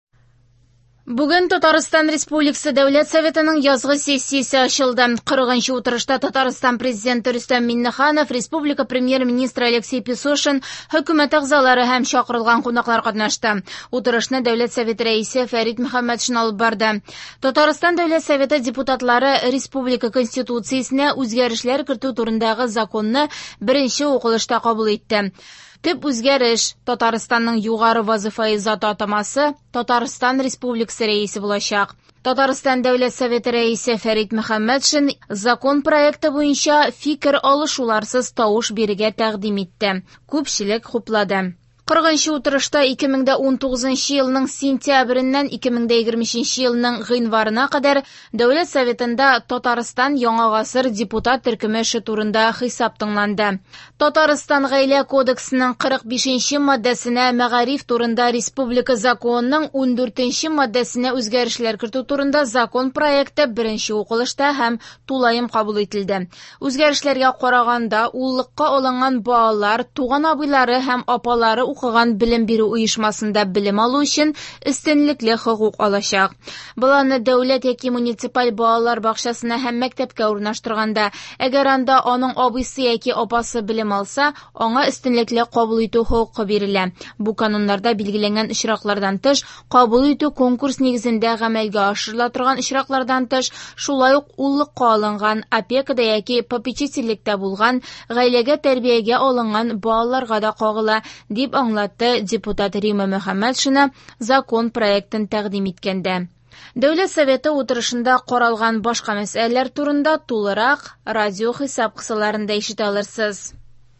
Радиоотчет (26.01.23)